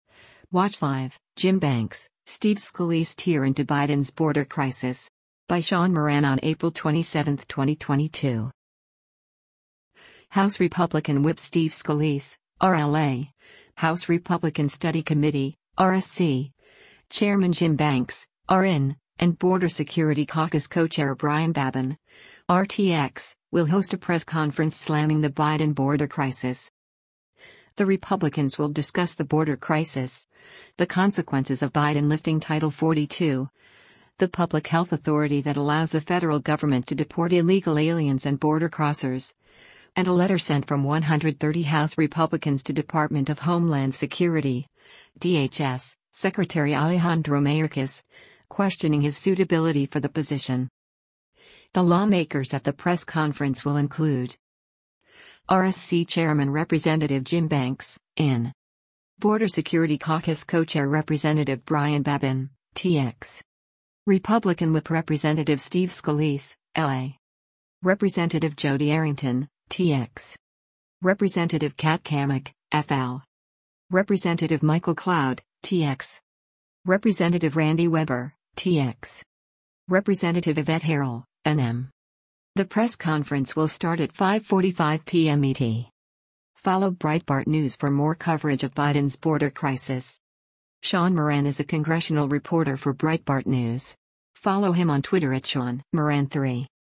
House Republican Whip Steve Scalise (R-LA), House Republican Study Committee (RSC) Chairman Jim Banks (R-IN), and Border Security Caucus Co-Chair Brian Babin (R-TX) will host a press conference slamming the Biden border crisis.
The lawmakers at the press conference will include: